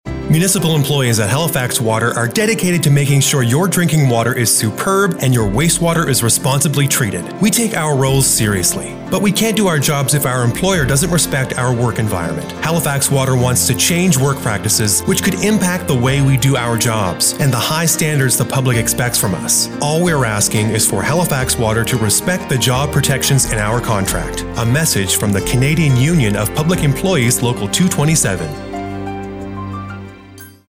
Radio ad: A message from Halifax Water workers - CUPE Nova Scotia